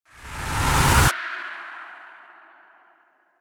FX-1446-WIPE
FX-1446-WIPE.mp3